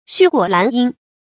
絮果蘭因 注音： ㄒㄩˋ ㄍㄨㄛˇ ㄌㄢˊ ㄧㄣ 讀音讀法： 意思解釋： 蘭因：比喻美好的結合；絮果：比喻離散的結局。